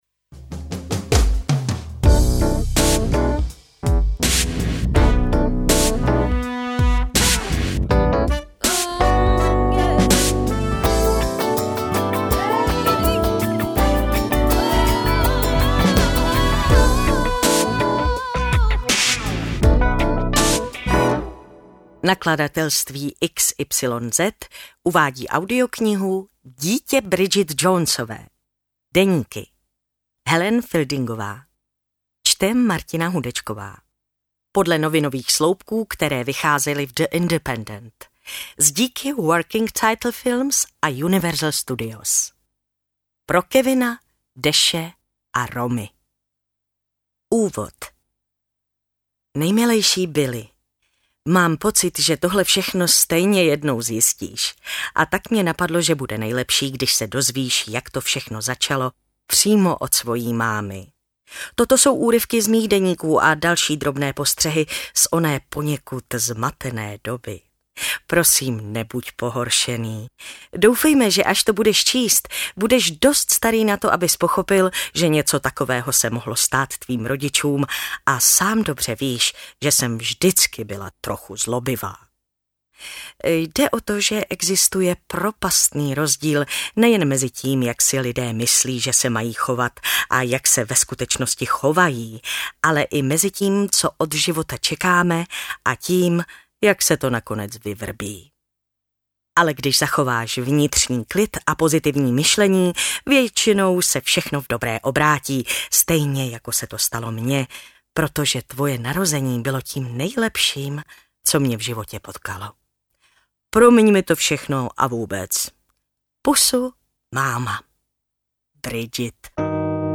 Interpret:  Martina Hudečková
AudioKniha ke stažení, 19 x mp3, délka 4 hod. 49 min., velikost 417,3 MB, česky